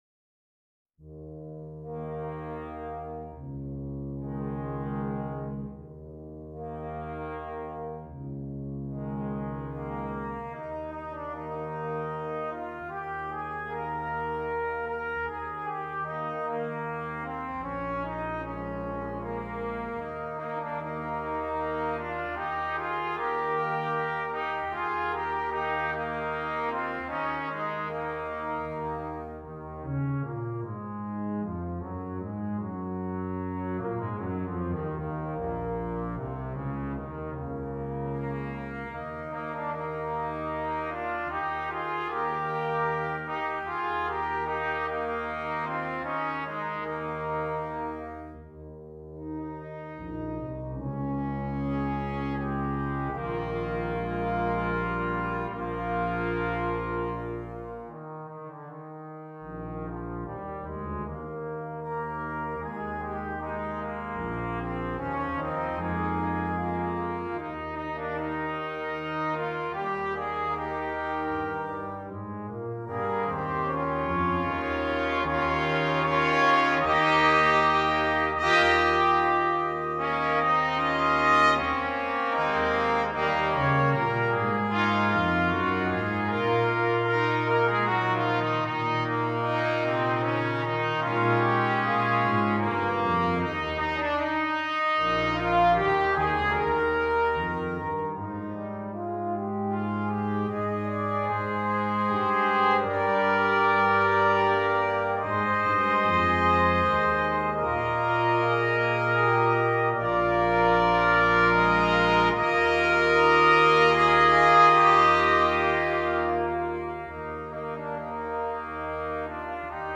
Voicing: Flexible Brass Trio